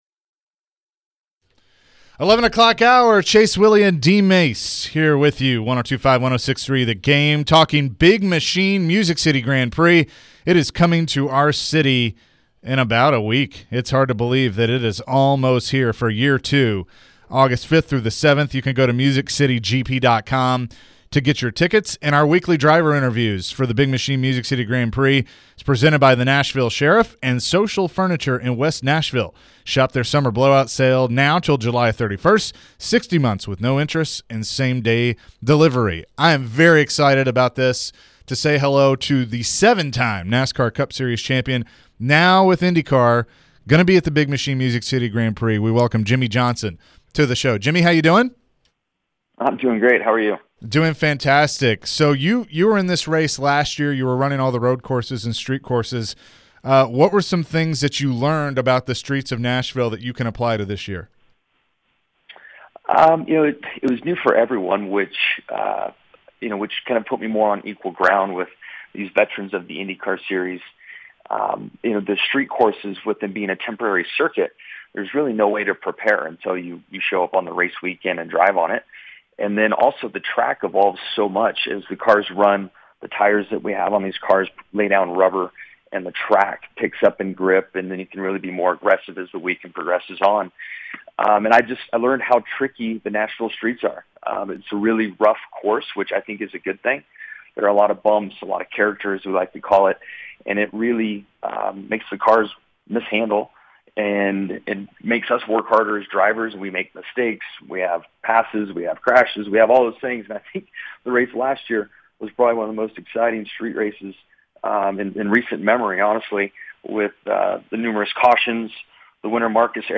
Jimmie Johnson Full Interview (07-26-22)